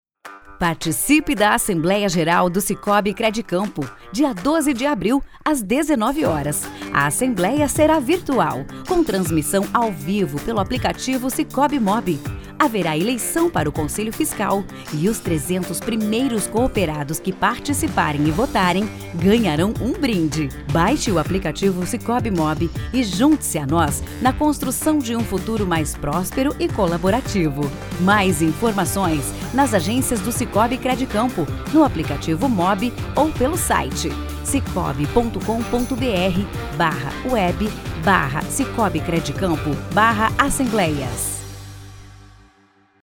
INSTITUCIONAL SICOOB: